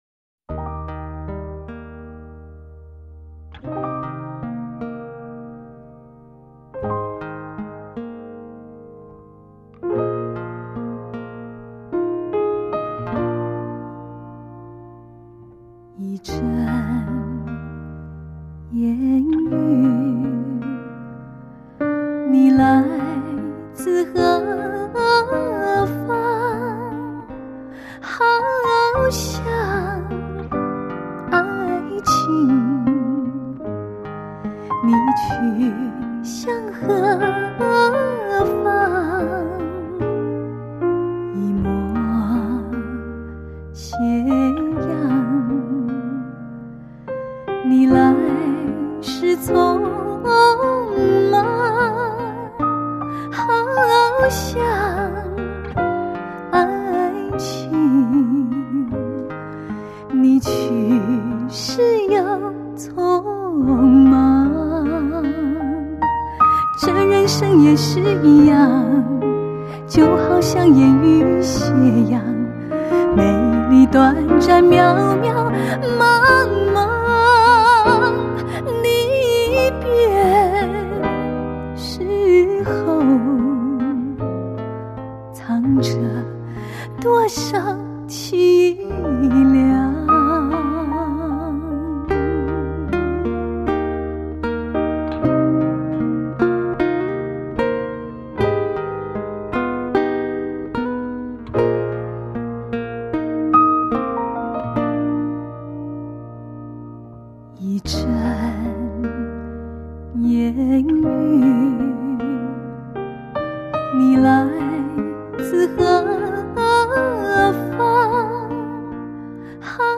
是以60-70年代最脍炙人口的的情歌为主，再增选几首不同时期的经典绝版，